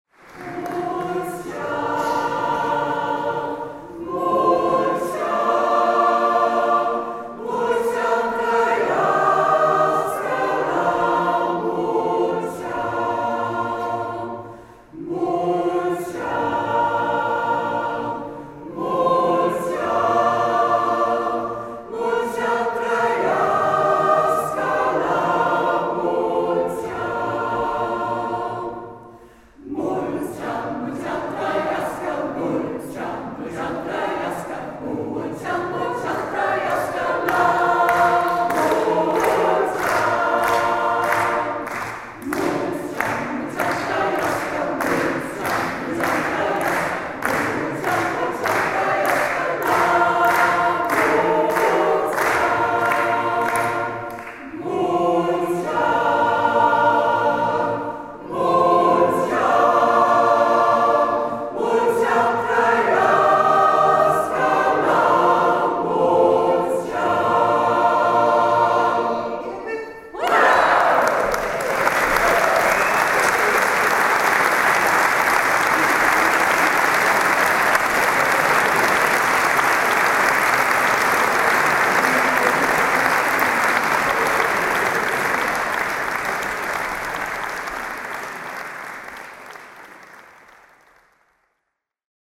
BLIJ RONDEEL SAMEN MET ROSMALENS MANNENKOOR